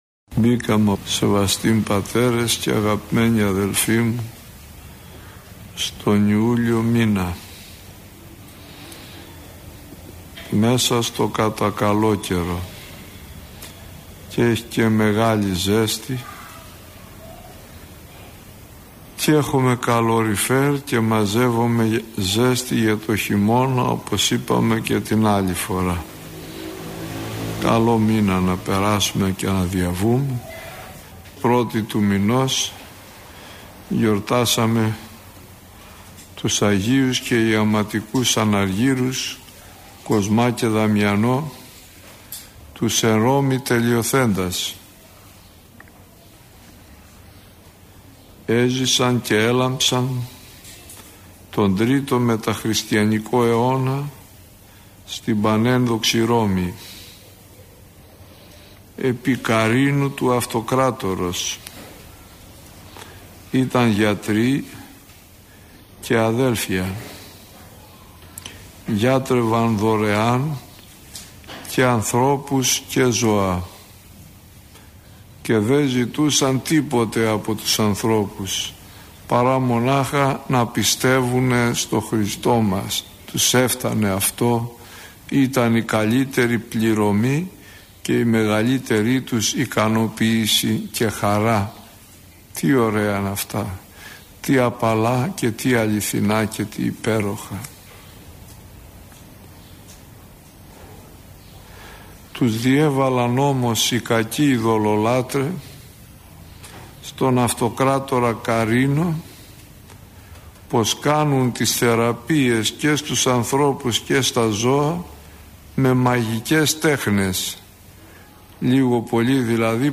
Άγιοι που εορτάζουν από 1 έως 7 Ιουλίου – ηχογραφημένη ομιλία